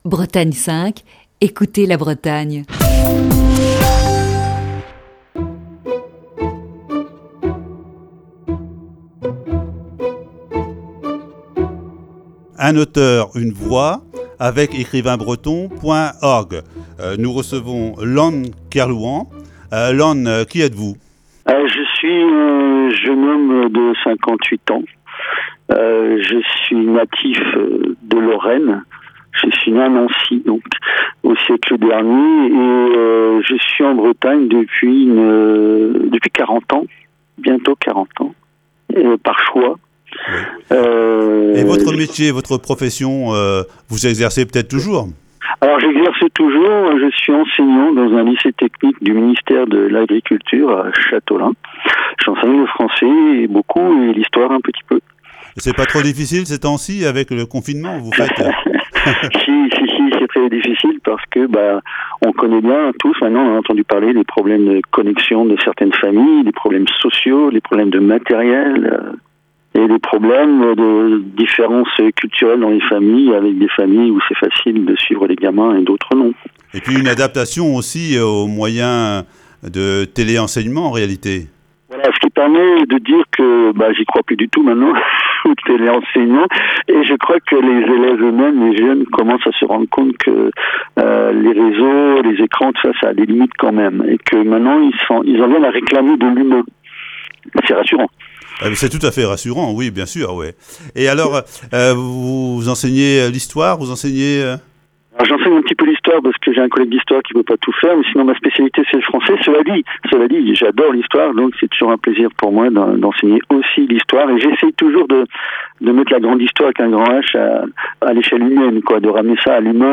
avec son invité au téléphone. Cette semaine dans Un auteur une voix
Voici ce lundi, la première partie de cet entretien.